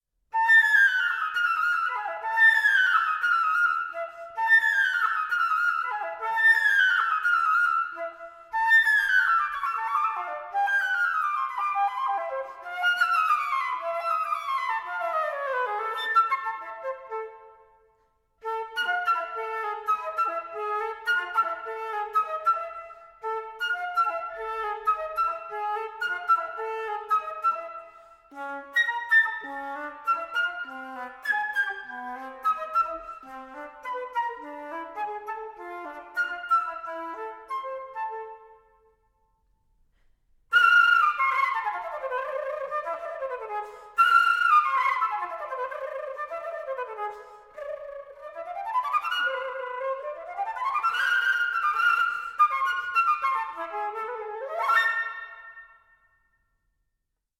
Stereo
arranged for solo flute
12 No 24 in A minor: Quasi presto